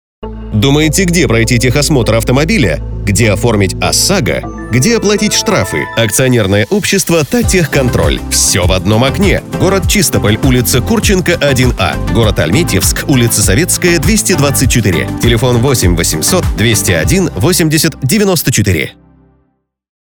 Началось размещение рекламы на радиостанции "Ретро FM" компании "Таттехконтроль" в г Чистополе.